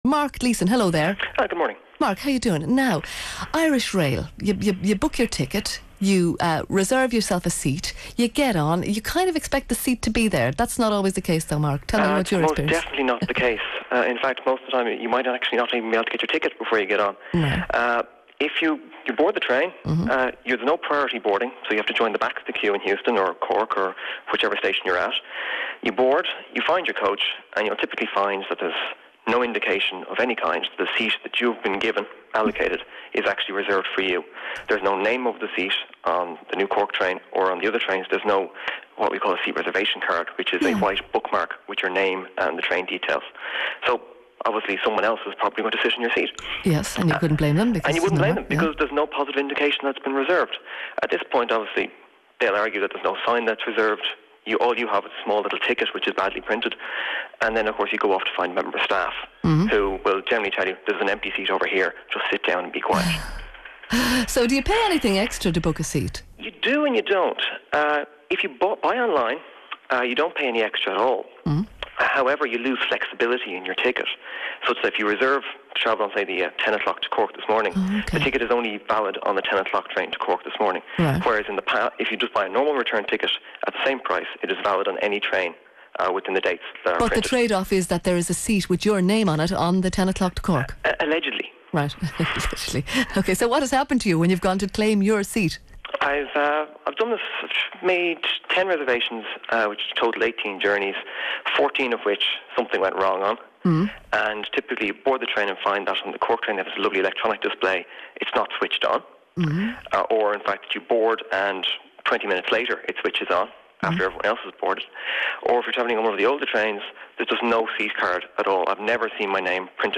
Discussion of the failures in the Irish Rail seat reservation system.